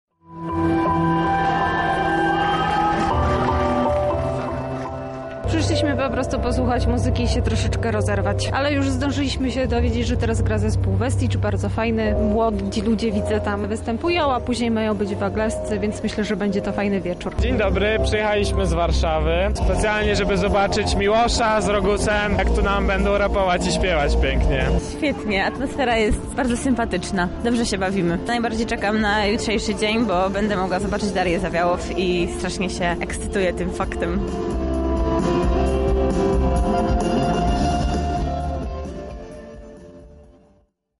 Na wydarzeniu gościli nasi reporterzy: